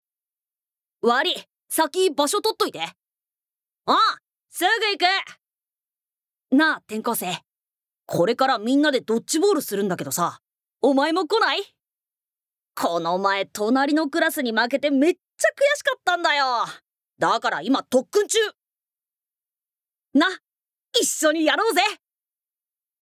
Voice Sample
ボイスサンプル
セリフ１